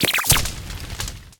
Cri de Charbambin dans Pokémon Écarlate et Violet.
Cri_0935_EV.ogg